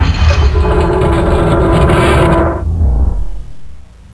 arab_squeak.wav